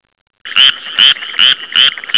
Frog 2
FROG_2.wav